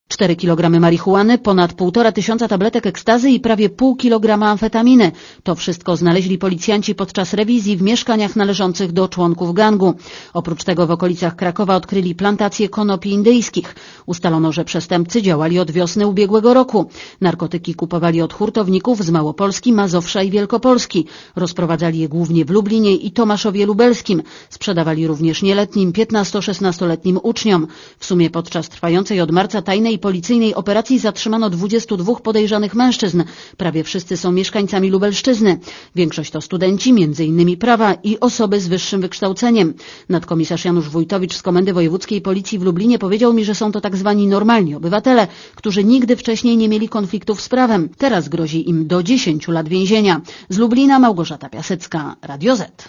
Posłuchaj relacji reporterki Radia Zet (205 KB)